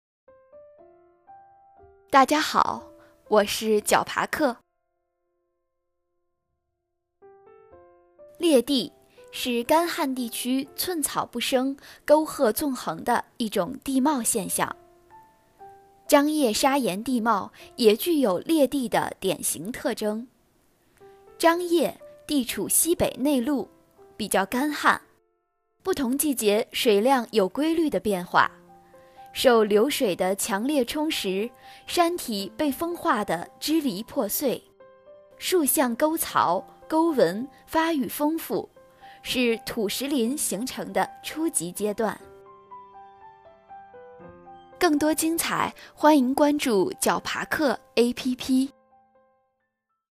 劣地式砂岩地貌----- 牛条龙 解说词: 劣地，是干旱地区寸草不生、沟壑纵横的一种地貌现象。